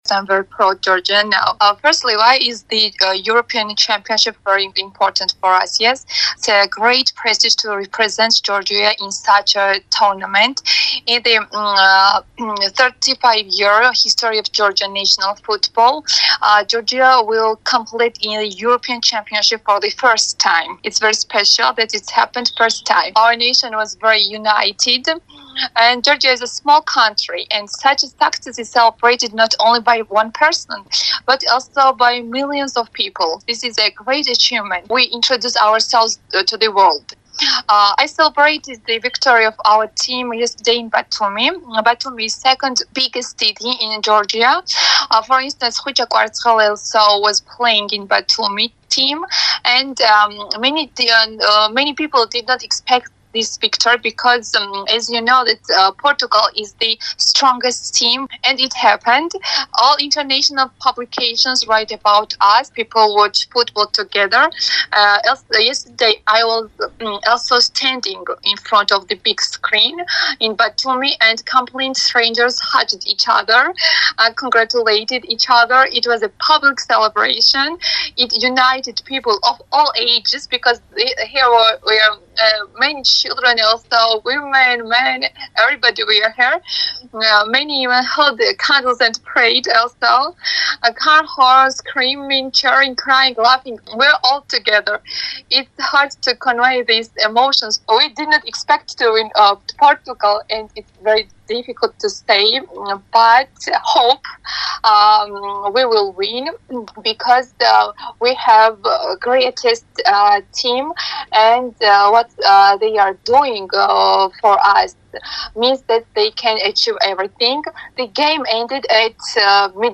Грузинка с емоционален разказ пред dsport: Чувствам се горда, всички излязохме по улиците и празнувахме обединени!